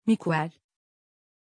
Aussprache von Miquel
pronunciation-miquel-tr.mp3